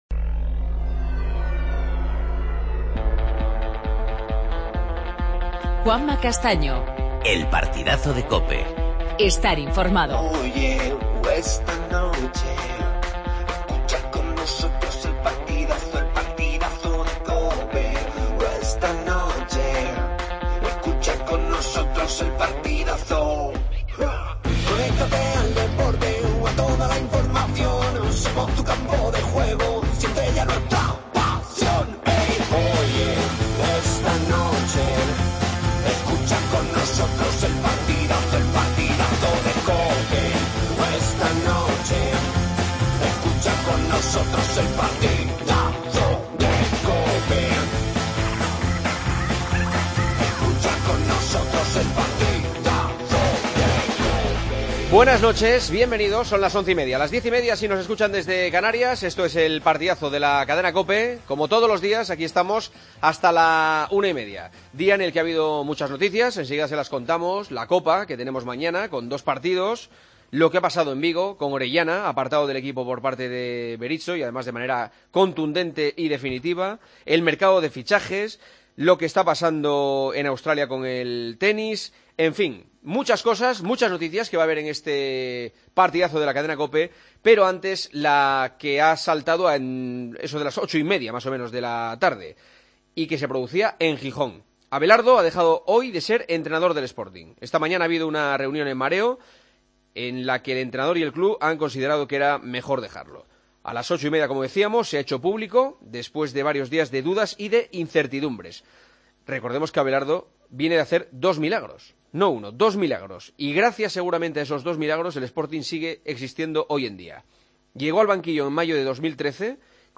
AUDIO: Titulares del día. Rubi sustituye a Abelardo en el banquillo del Sporting.